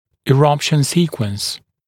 [ɪ’rʌpʃn ‘siːkwəns][и’рапшн ‘си:куэнс]последовательность прорезывания (зубов)